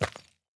immersive-sounds / sound / footsteps / resources / ore-06.ogg
ore-06.ogg